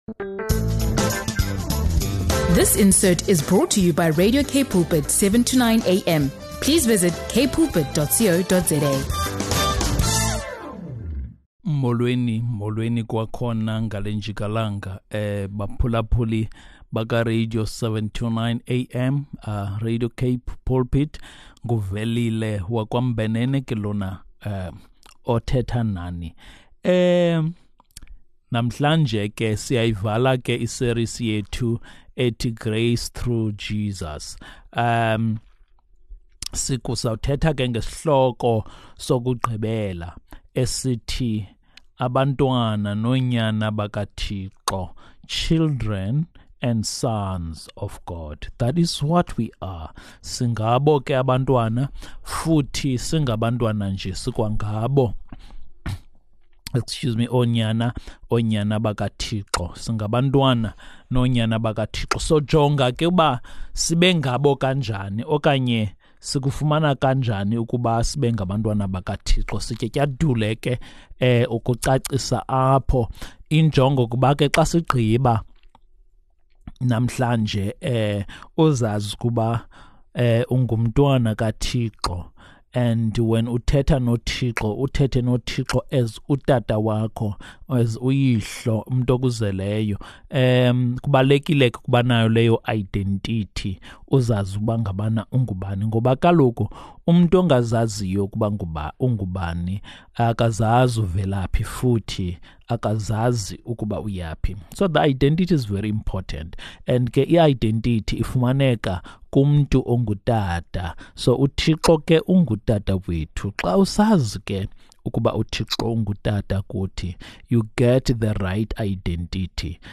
Discover how embracing your relationship with Christ can change your life, strengthen your faith, and foster a sense of belonging in God's family. Join us for uplifting Xhosa sermons that encourage spiritual growth and deepen your understanding of grace and faith.